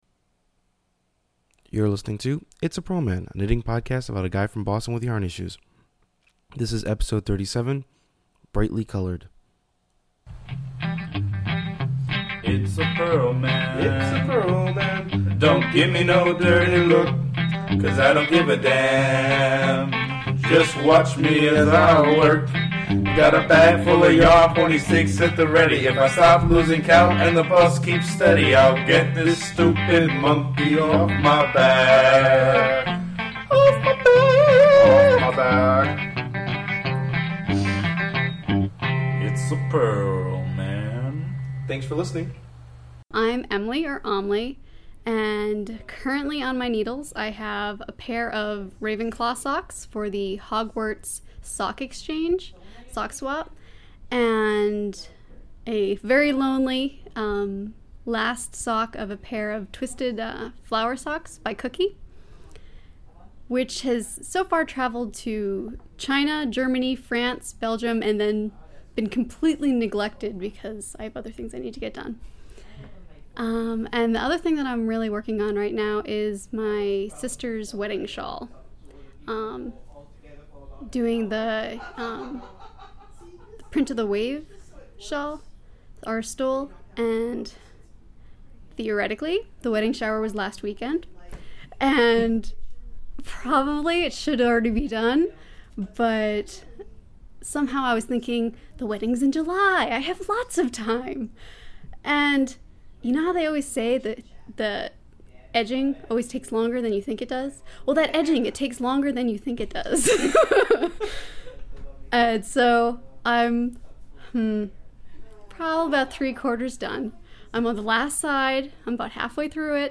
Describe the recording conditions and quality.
Too bad I kept turning away from the microphone - sorry, I fade in and out a bit. The interview got slightly cut off in the beginning.